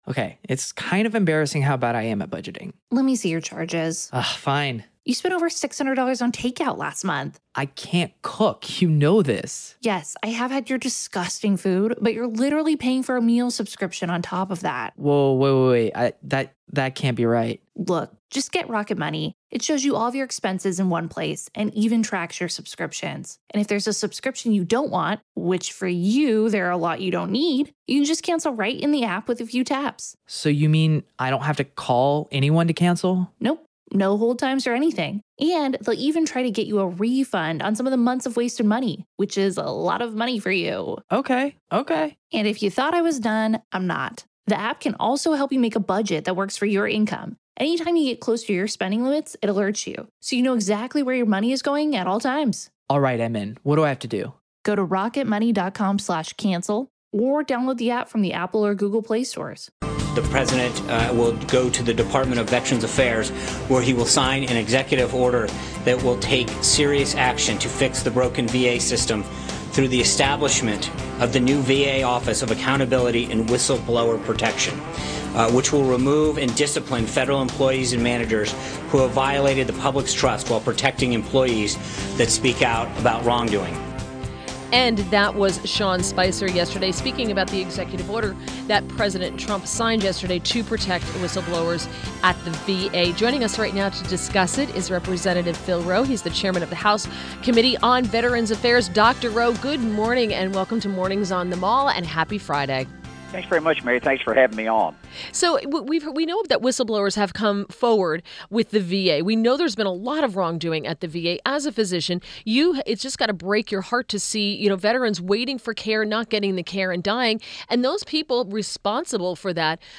WMAL Interview - REP. PHIL ROE -04.28.17
INTERVIEW – REP. PHIL ROE, M.D. (R-TN), Chairman of the House Committee on Veterans’ Affairs